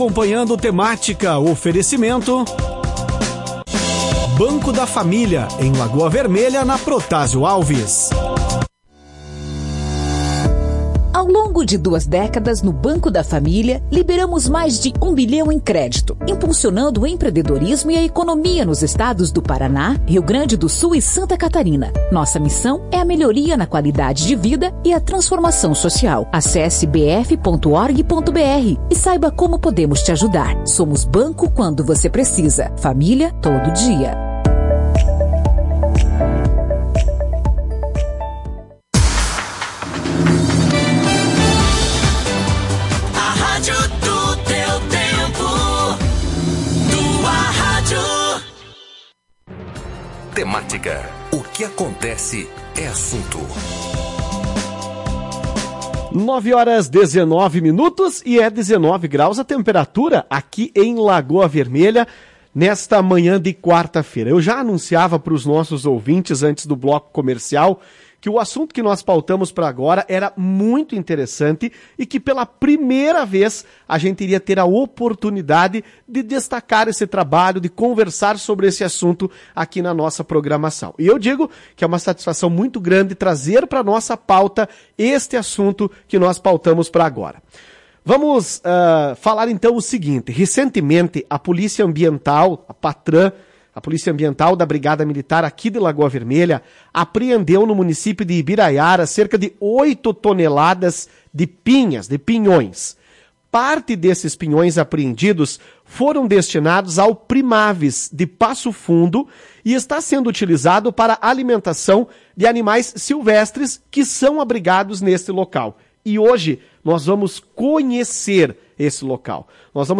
Na manhã desta quarta-feira, 13 de abril, o programa Temática da Tua Rádio Cacique fez conexão com Passo Fundo, onde está o Primaves. O abrigo de animais silvestres recebeu parte da carga de pinhões apreendidos pela Patram, em Ibiraiaras, na última semana.